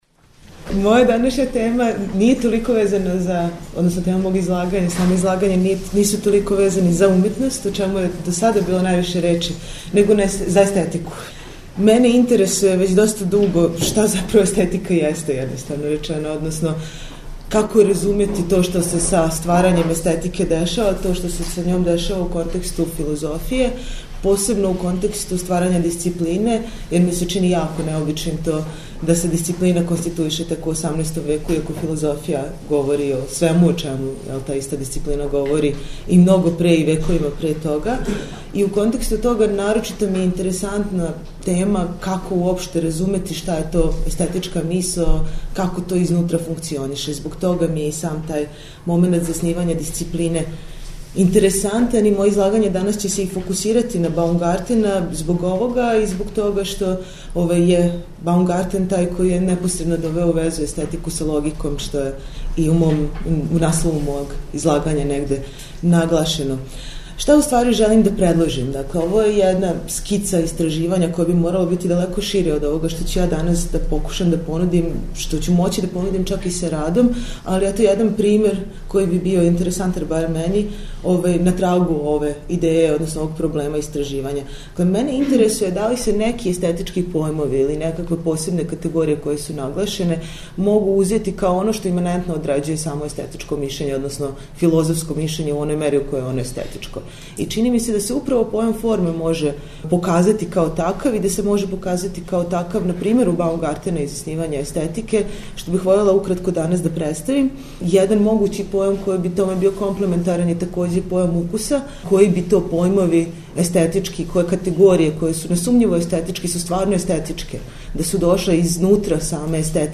Научни скупови